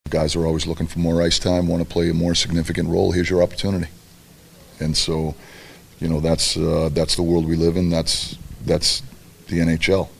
Coach Mike Sullivan says, Crosby or not, whoever’s on the ice tonight for the Pens needs to step up.